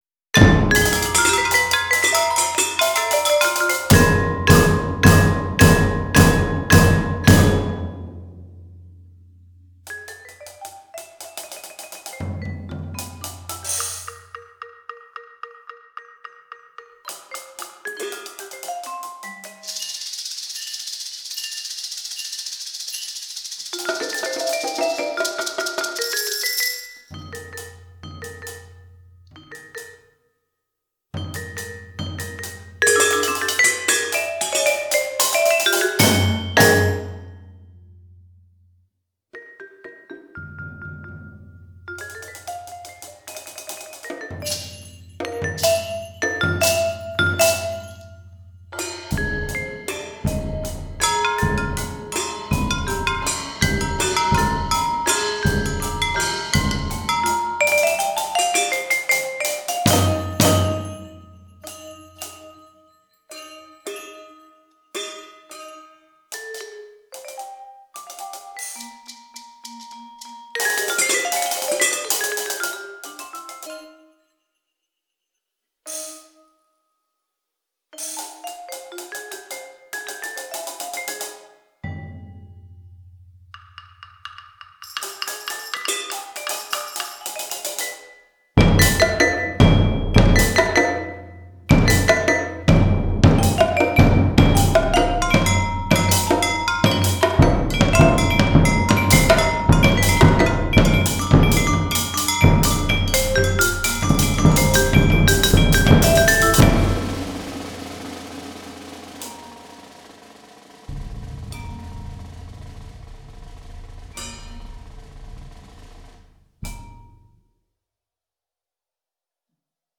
Chatarras y Cacerolas para percusión